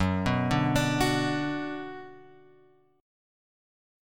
F#sus4#5 chord {2 2 0 x 3 2} chord